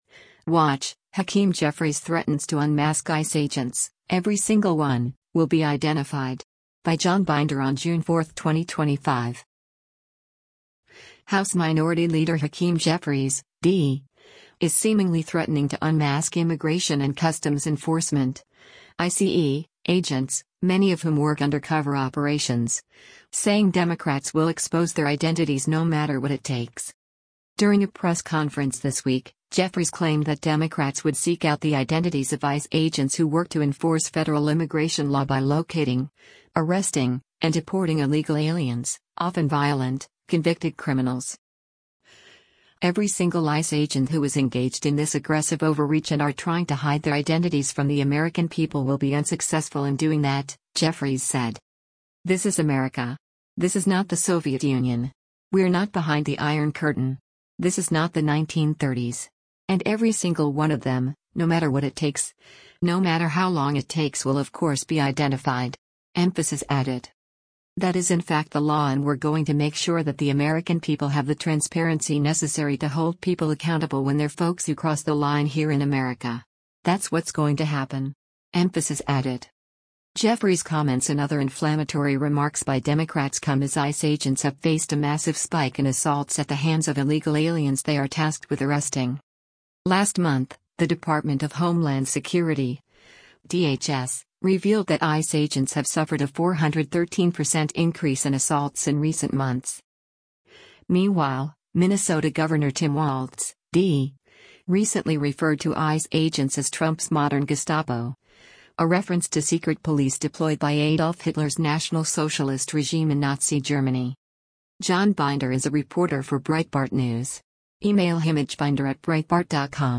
During a press conference this week, Jeffries claimed that Democrats would seek out the identities of ICE agents who work to enforce federal immigration law by locating, arresting, and deporting illegal aliens — often violent, convicted criminals.